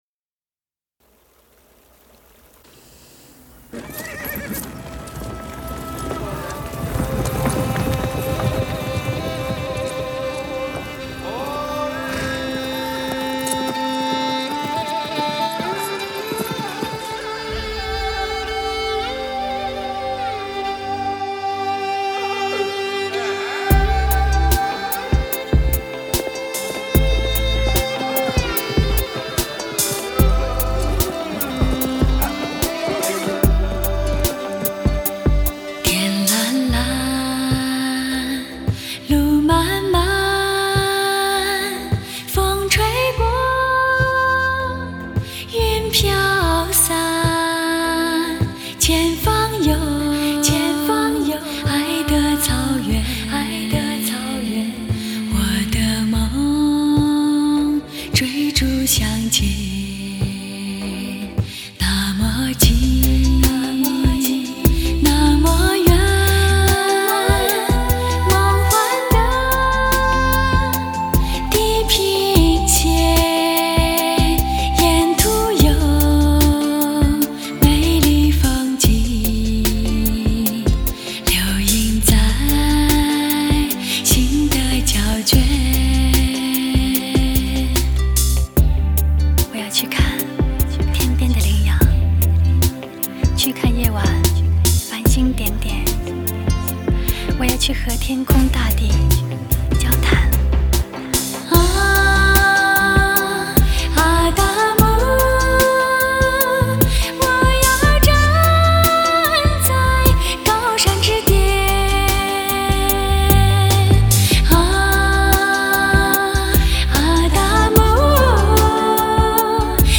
音质极度传真！测试器材之首选天碟
人声